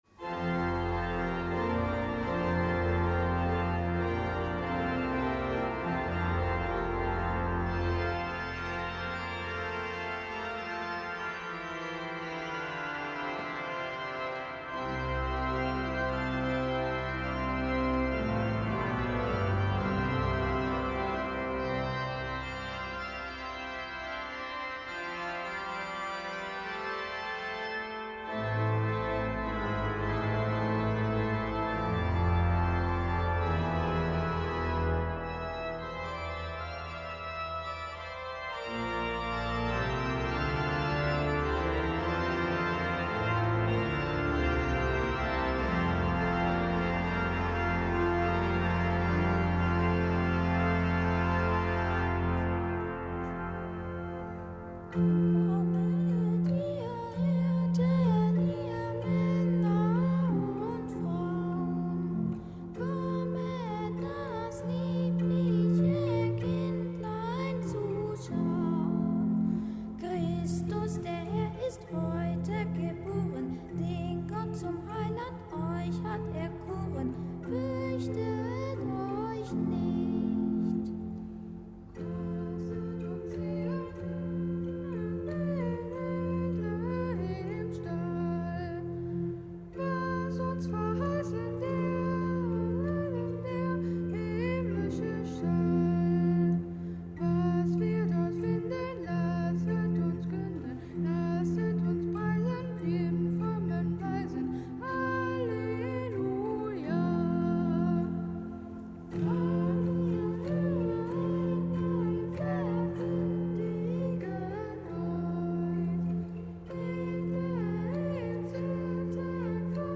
Gottesdienst
Kapitelsamt im Kölner Dom am Fest der Heiligen Familie.